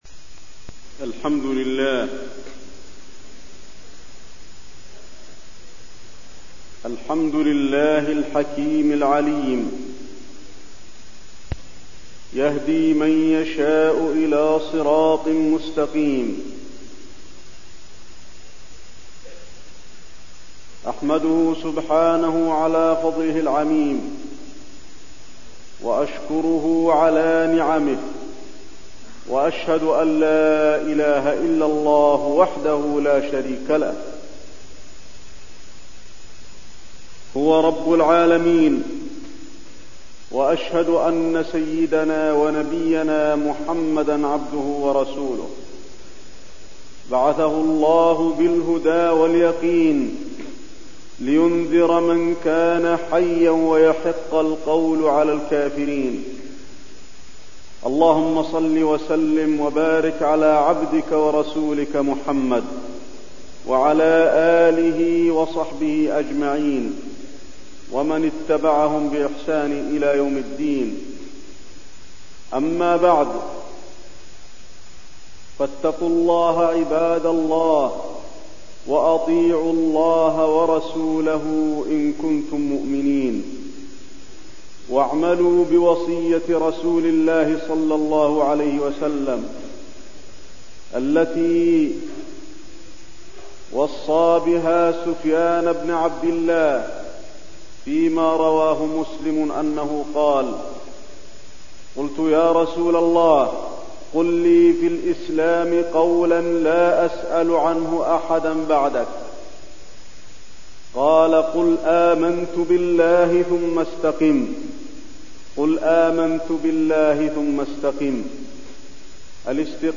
خطبة الاستقامة وفيها: أنها أعلى مراتب الدين وعلامة رضا الله على العبد، وما هي الاستقامة؟، وأن استقامة الجوارح من استقامة القلب
تاريخ النشر ١٧ محرم ١٤٠٢ المكان: المسجد النبوي الشيخ: فضيلة الشيخ د. علي بن عبدالرحمن الحذيفي فضيلة الشيخ د. علي بن عبدالرحمن الحذيفي الاستقامة